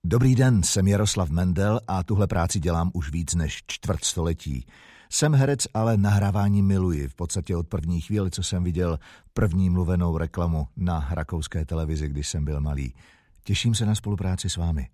Native speaker Male 20-30 lat
Experienced voice artist whose native language is Czech.
Demo lektorskie CZ